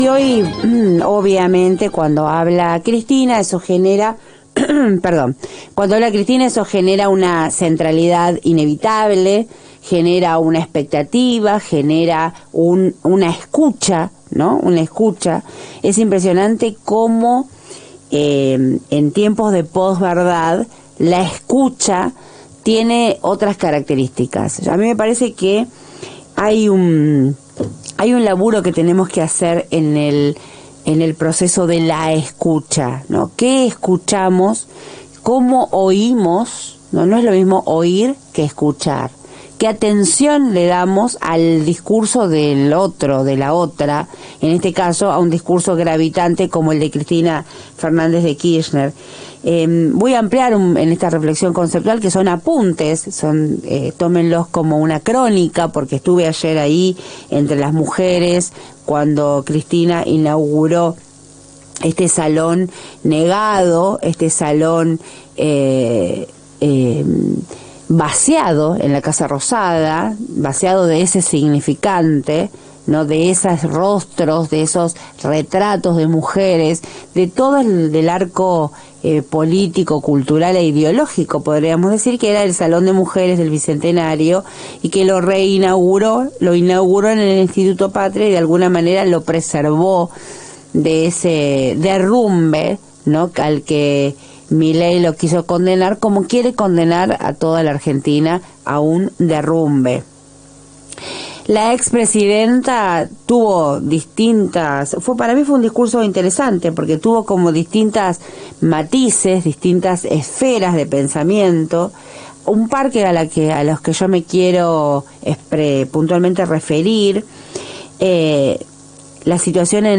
A partir de un tramo del discurso, demostró como la construcción de la post verdad que viralizo un video real cambiándole el contenido, con una frase que no se había dicho. Escucharemos el audio cambiado y el real.